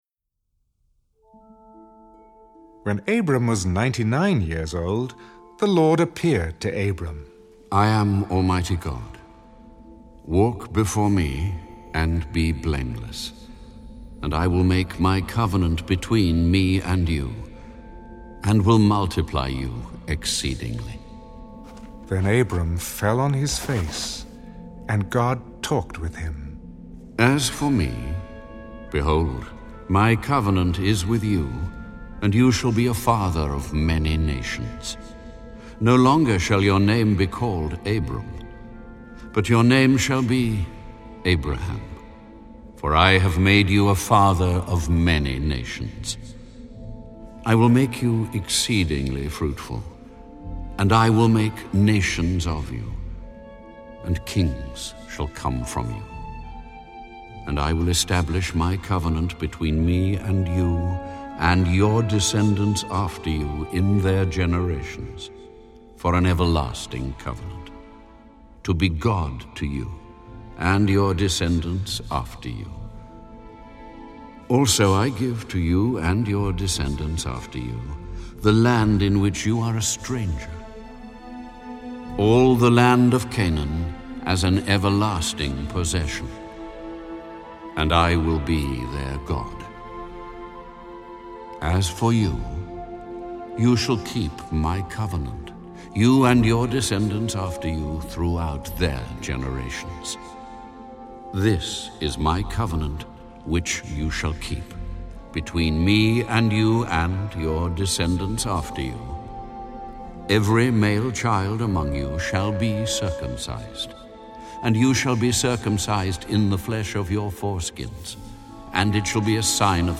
(Audio Bible)